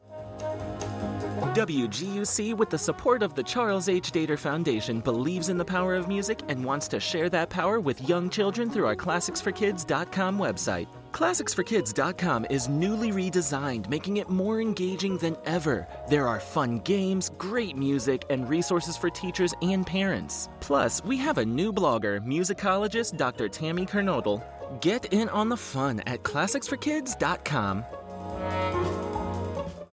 Inglés (Estados Unidos)
Adulto joven
Mediana edad